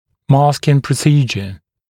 [‘mɑːskɪŋ prə’siːʤə][‘ма:скин прэ’си:джэ]маскирующая процедура, мера, операция